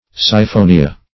Siphonia \Si*pho"ni*a\, n. [NL.] (Bot.)